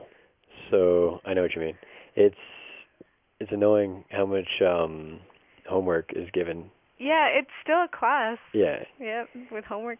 Functions of Bookended Narrow-Pitch-Range Regions
2. Complaining